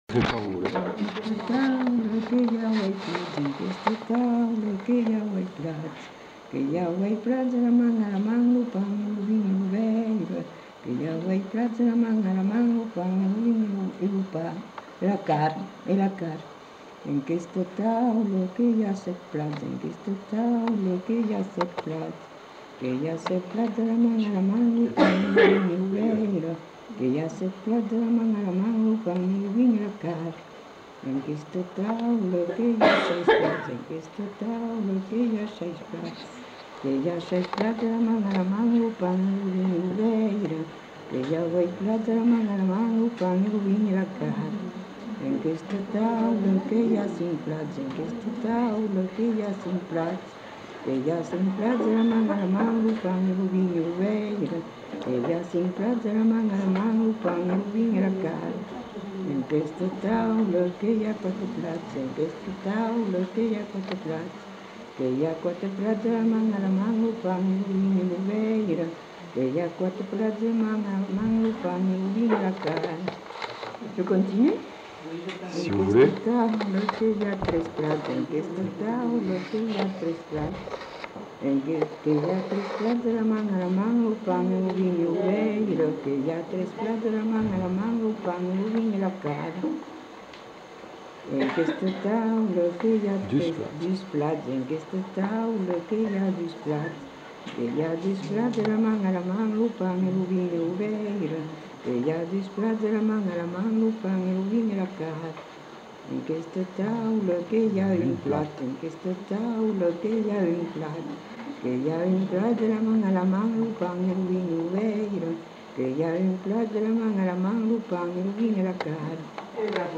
Lieu : Allons
Genre : chant
Effectif : 1
Type de voix : voix de femme
Production du son : chanté
Danse : rondeau